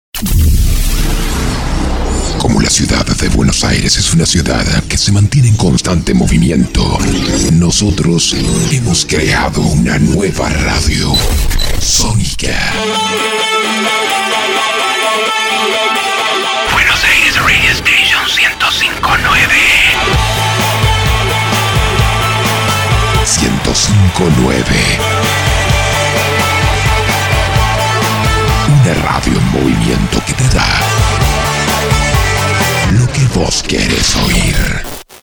spanisch SĂŒdamerika
chilenisch
Sprechprobe: Werbung (Muttersprache):
I have 4 different styles of voice and I can come in neutral tones and as agile and high tones.